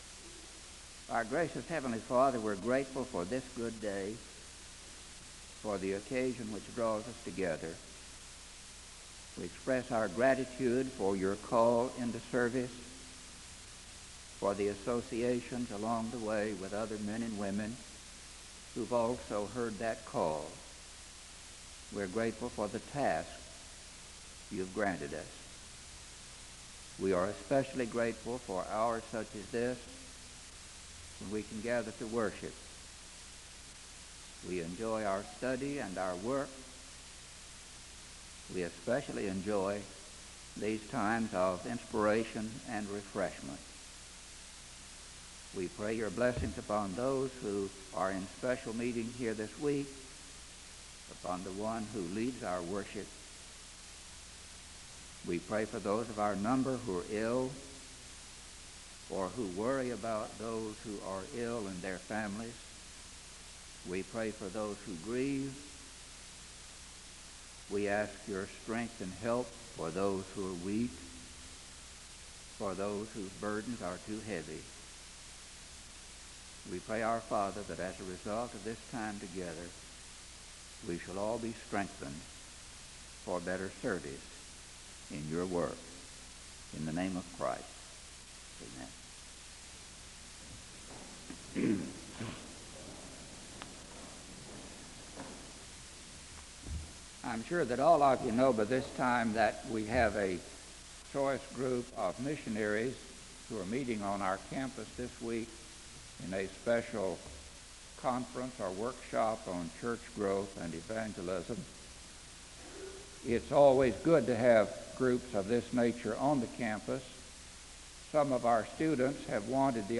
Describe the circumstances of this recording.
The service begins with a word of prayer (00:00-01:30).